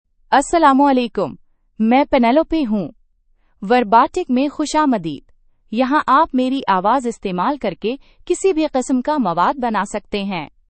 Penelope — Female Urdu (India) AI Voice | TTS, Voice Cloning & Video | Verbatik AI
Penelope is a female AI voice for Urdu (India).
Voice sample
Listen to Penelope's female Urdu voice.
Female
Penelope delivers clear pronunciation with authentic India Urdu intonation, making your content sound professionally produced.